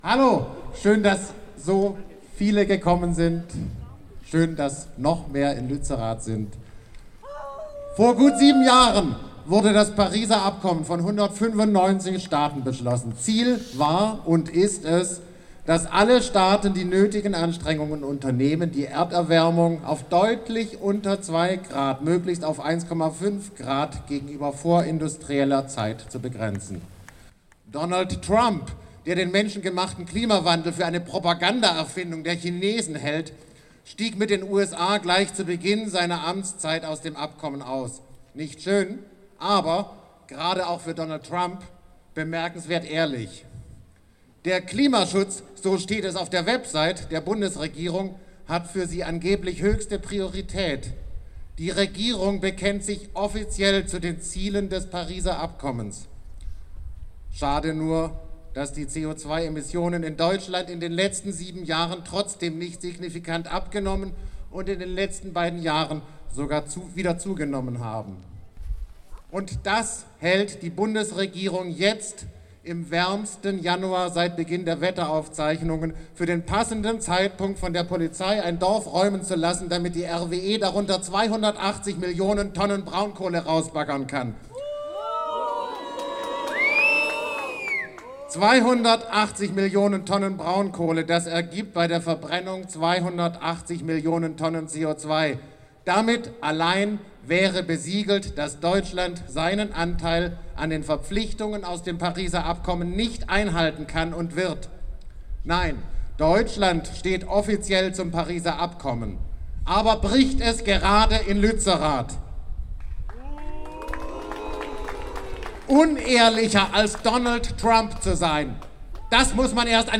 600 Menschen auf Freiburger Solidaritätskundgebung gegen die Räumung Lützeraths: "Power to the people!"
Am 14. Januar 2023 versammelten sich etwa 600 Menschen auf dem Rathausplatz, direkt neben dem Klimacamp Freiburg, um sich solidarisch mit den Aktivist*innen in Lützerath zu zeigen.
Darauf folgte eine Rede von Extinction Rebellion Freiburg, in der Solidarität mit den Menschen vor Ort bekundet und Lützerath als Symbol des Widerstands gewürdigt wurde.